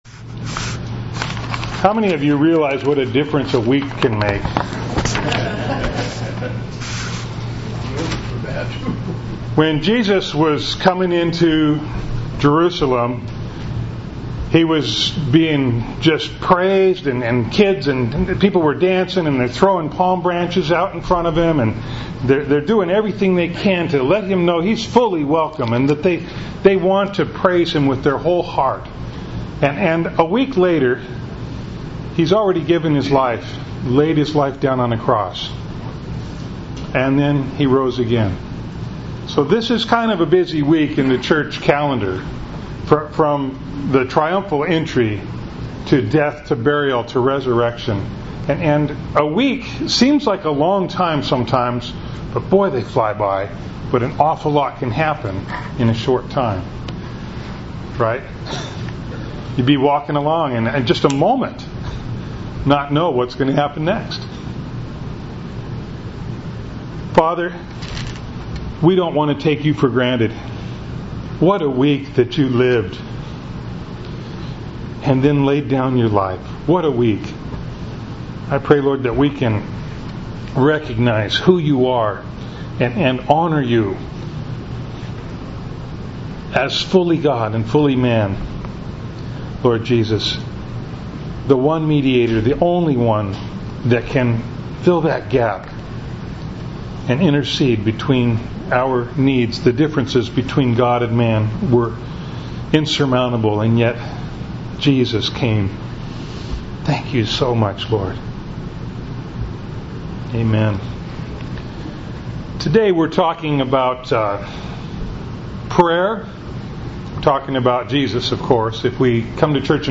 Passage: 1 Timothy 2:1-7 Service Type: Sunday Morning